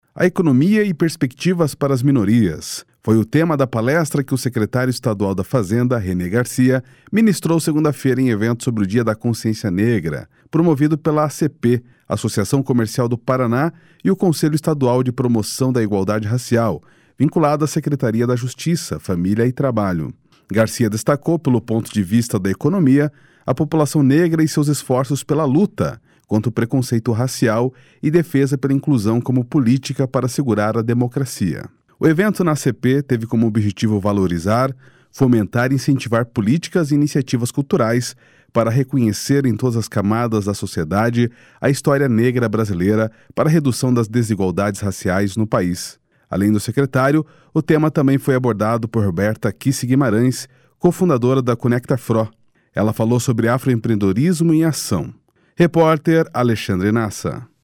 Secretário da Fazenda fala sobre economia e minorias em evento do Dia da Consciência Negra